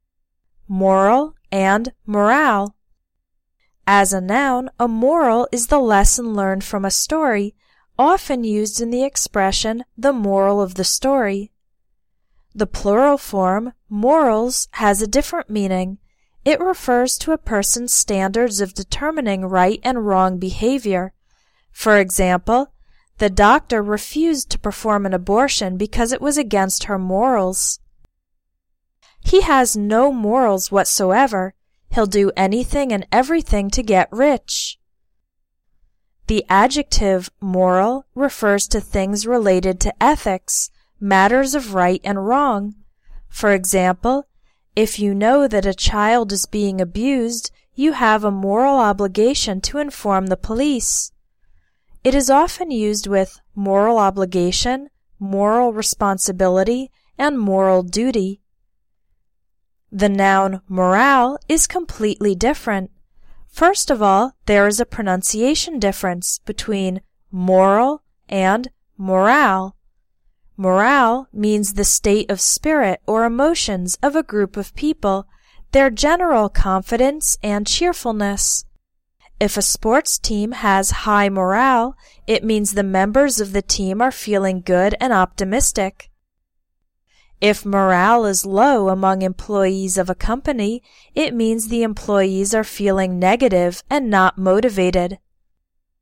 First of all, there is a pronunciation difference:
• moral: MOR – al
• morale: mor – ALE